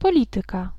Ääntäminen
Ääntäminen Tuntematon aksentti: IPA: /pɔˈlit̪ɨka/ Haettu sana löytyi näillä lähdekielillä: puola Käännös Ääninäyte Substantiivit 1. politics US UK 2. policy US Suku: f .